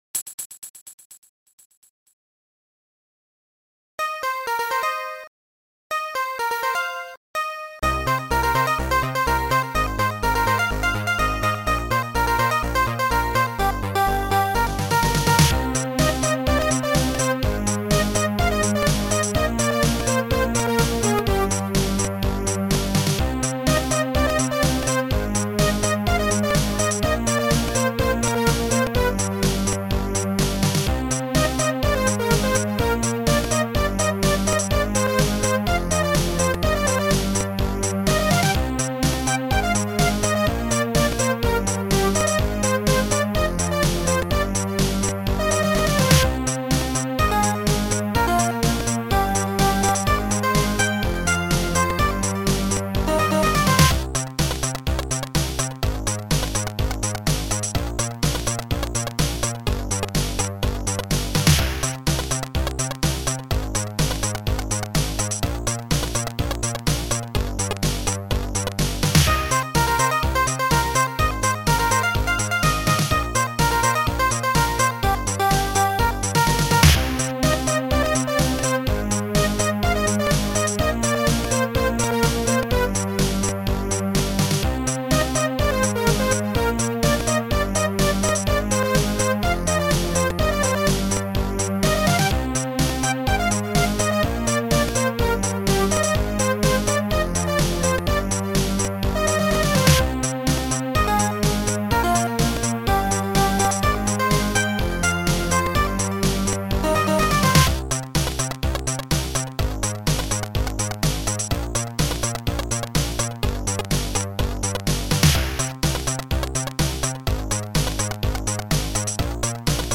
Sound Format: Soundtracker 15 Samples